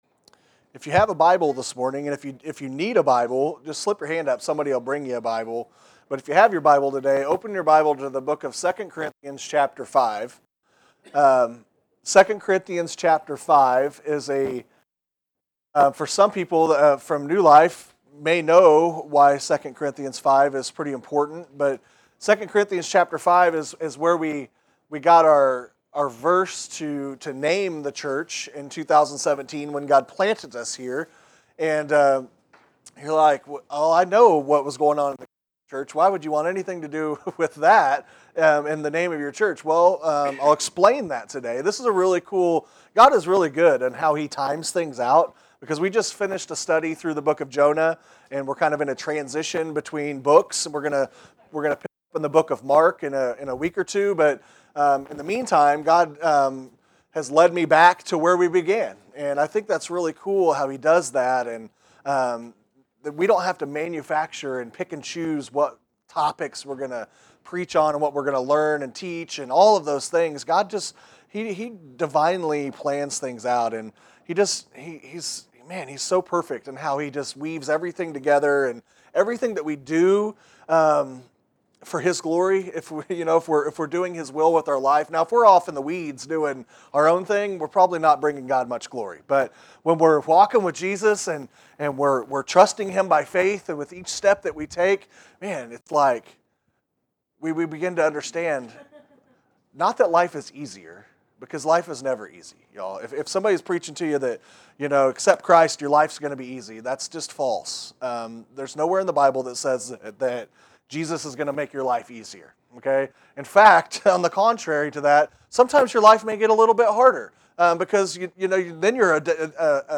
Sermons Archive - Page 3 of 52 - New Life Baptist Church of Clinton MO